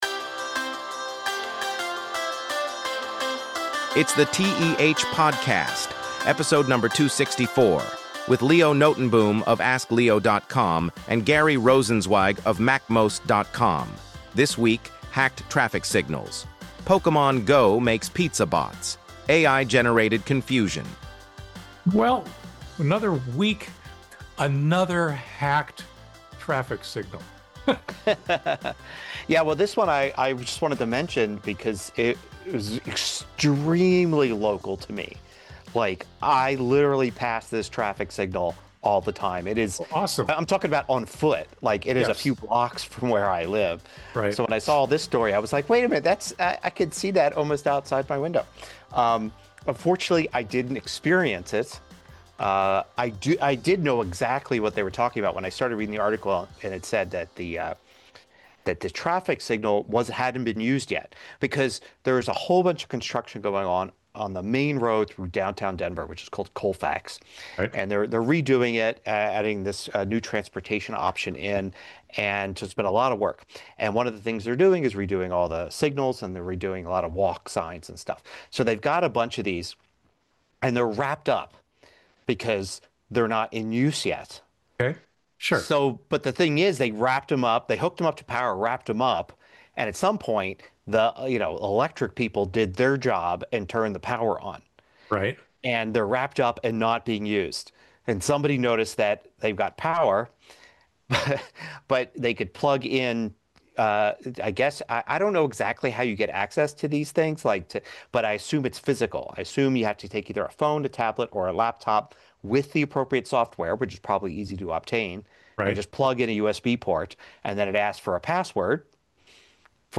Top Stories